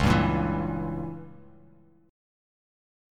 C#dim7 chord